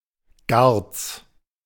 Gartz (pronunciación en alemán: /ɡaʁt͡s/ (